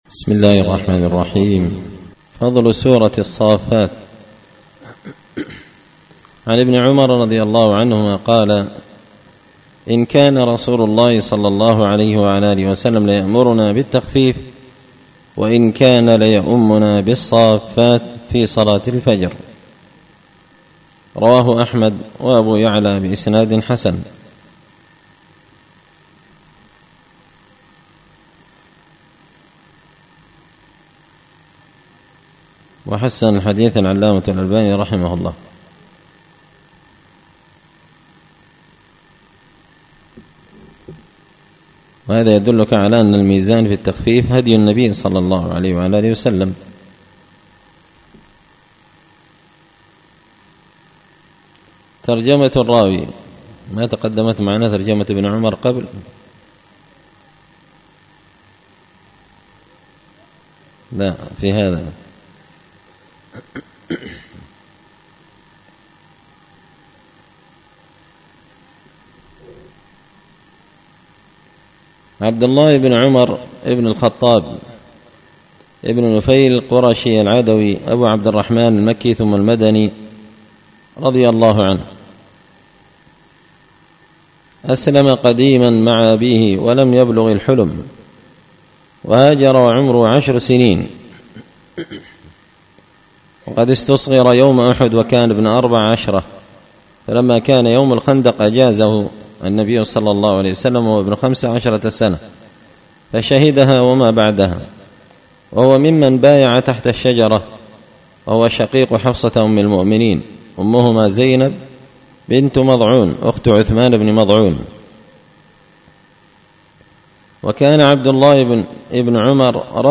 الأحاديث الحسان فيما صح من فضائل سور القرآن ـ الدرس الرابع والثلاثون